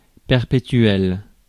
Ääntäminen
Vaihtoehtoiset kirjoitusmuodot (vanhentunut) eternall (vanhentunut) æternal (vanhentunut) æternall Synonyymit permanent endless everlasting sempiternal timeless atemporal unlimited neverending Ääntäminen US